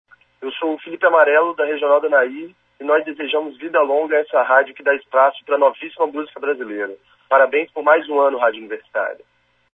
Confira os depoimentos: